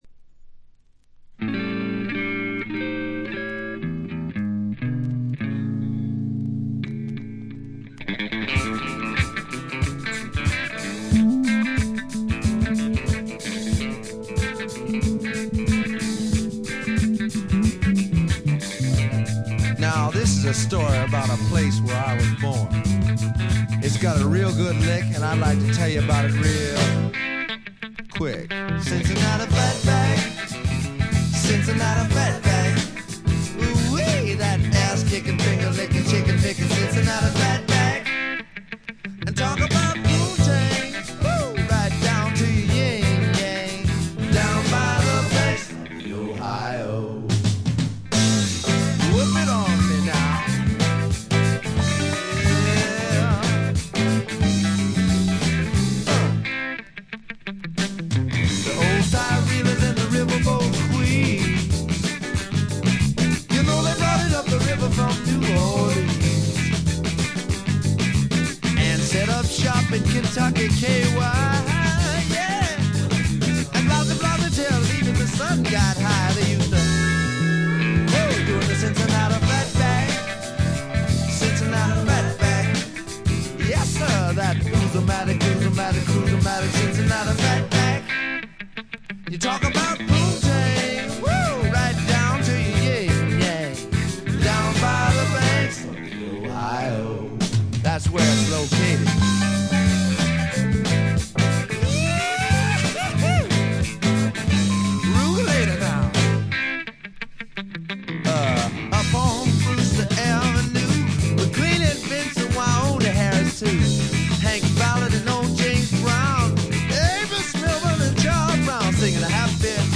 部分試聴ですが、ごくわずかなノイズ感のみ。
これはもう最高のR&B／ロックンロールと言うしかないでしょう。
試聴曲は現品からの取り込み音源です。